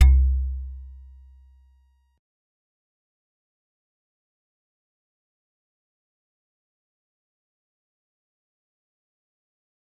G_Musicbox-C2-pp.wav